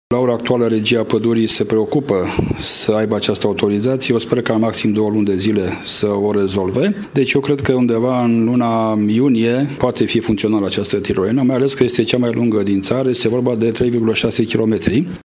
Administraţia Municipiului Săcele dă asigurări, însă, că, în scurt timp, turiştii amatori de senzaţii tari se vor putea bucura, din nou, de acest mod de relaxare, a declarat Virgil Popa , primarul Municipiului Săcele.